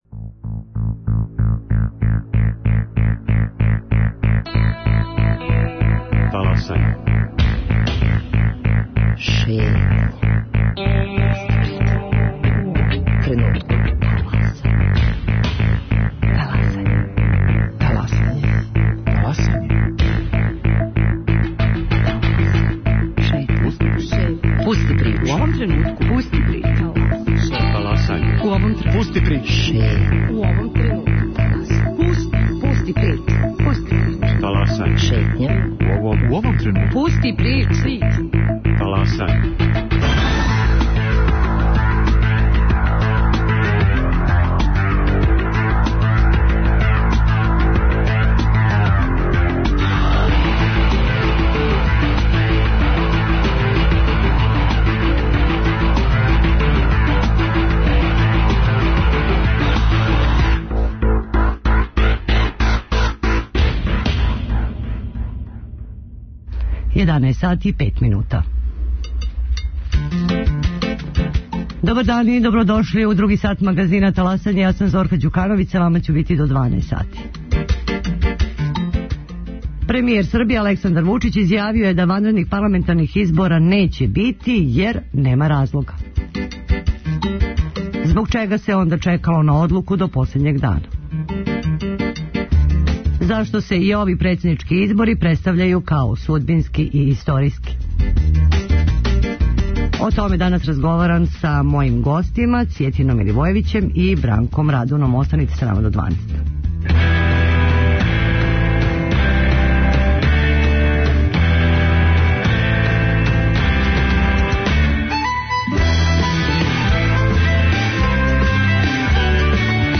Гости: политички аналитичари